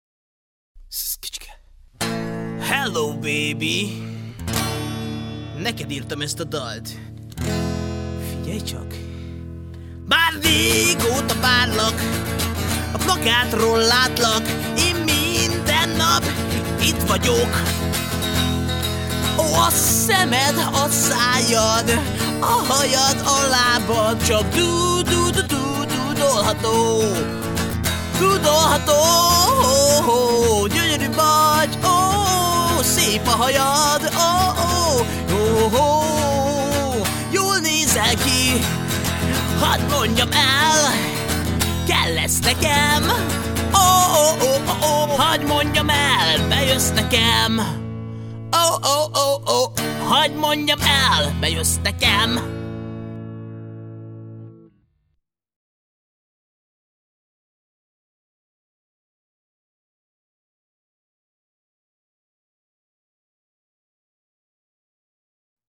Punk zene, 1.5 MB